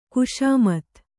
♪ kuśamat